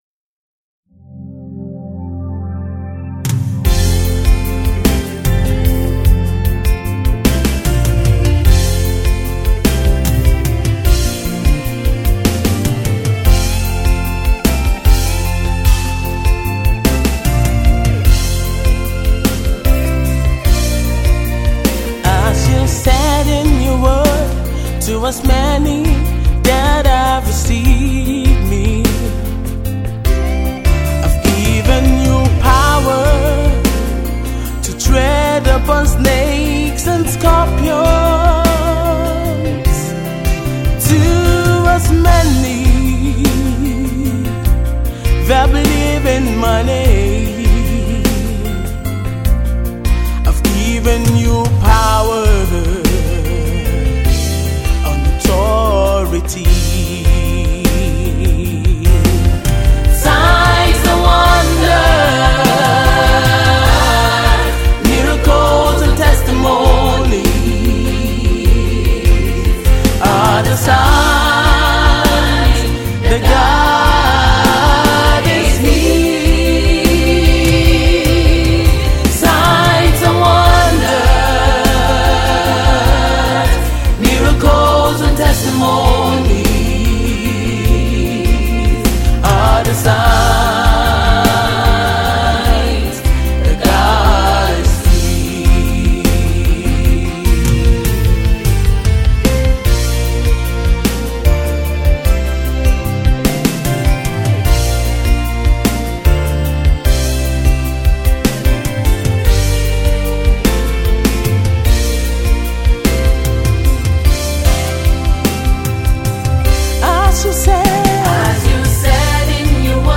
Nigeria-based Gospel Music Singer